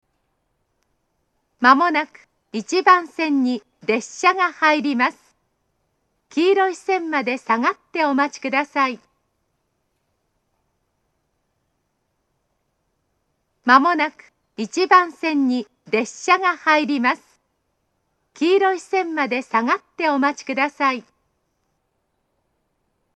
１番線接近放送
スピーカーは全体的に高めです。
koriyama1bansen-sekkin.mp3